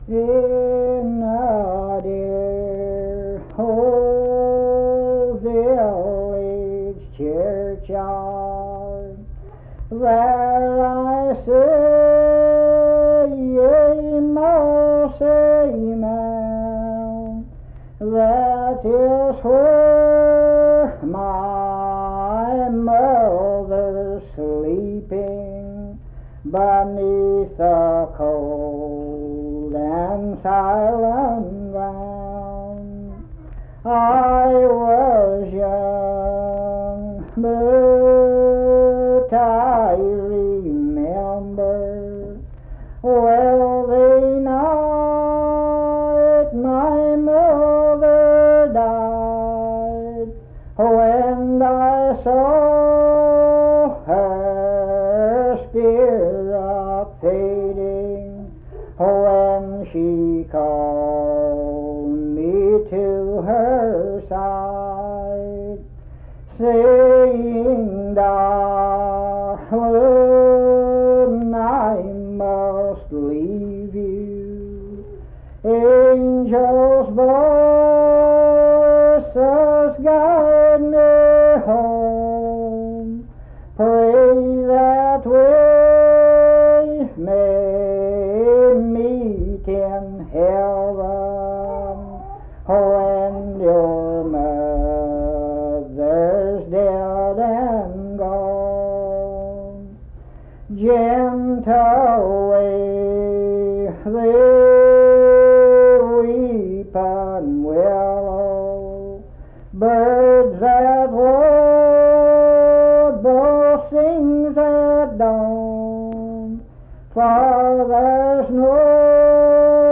Unaccompanied vocal music
Verse-refrain 6(4).
Performed in Naoma, Raleigh County, WV.
Voice (sung)